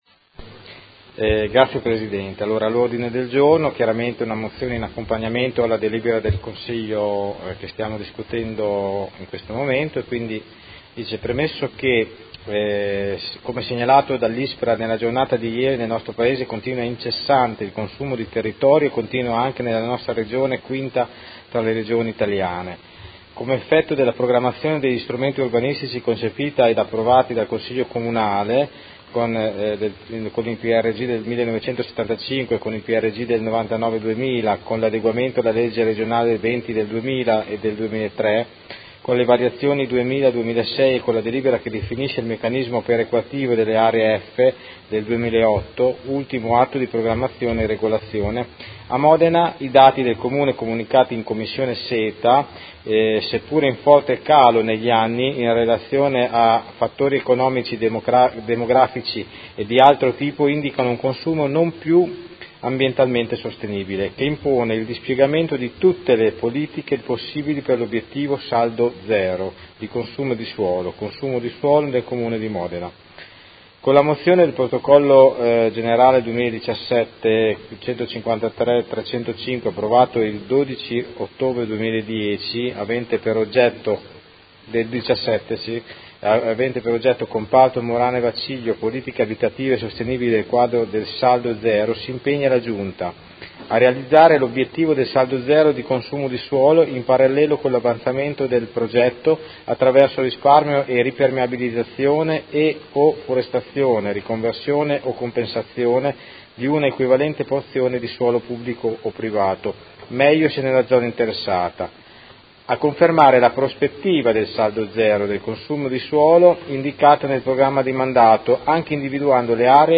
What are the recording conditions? Seduta del 19/07/2018 Presenta ordine del giorno nr. 110910